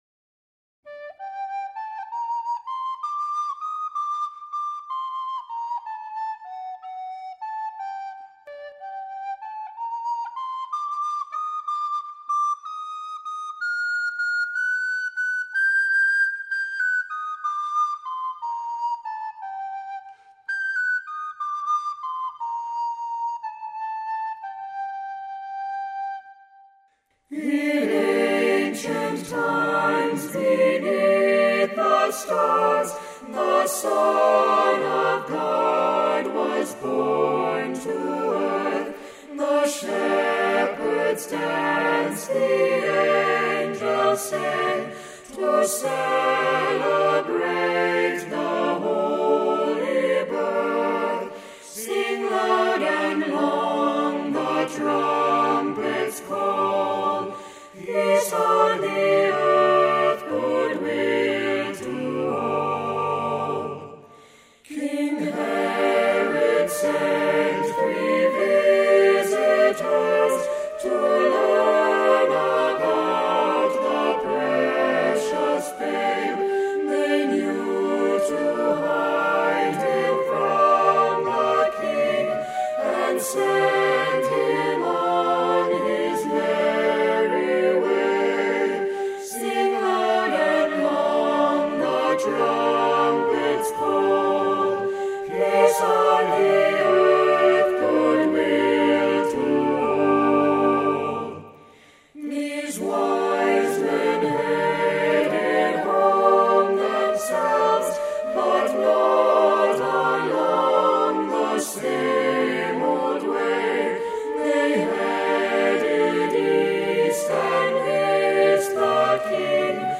a carol
Play Song Includes a nice recorder or flute part.